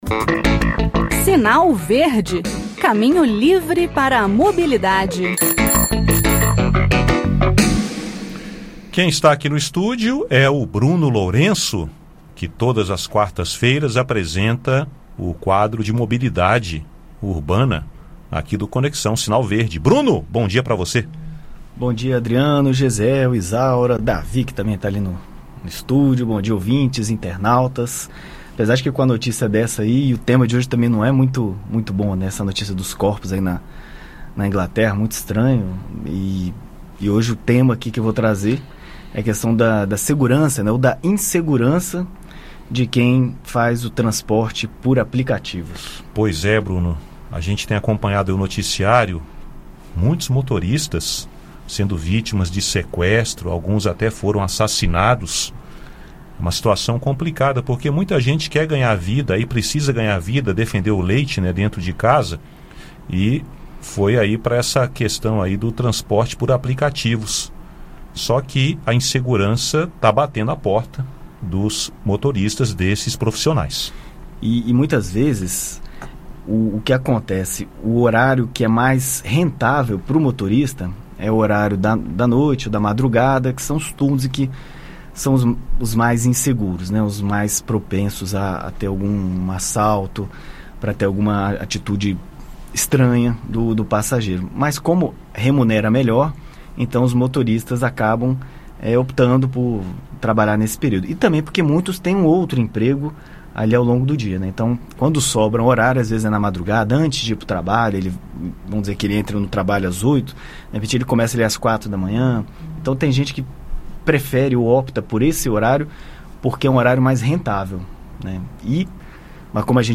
No "Sinal Verde" desta quarta-feira (23), o assunto são recomendações para a segurança de motoristas de aplicativo, taxistas e passageiros. Ouça o áudio com o bate-papo.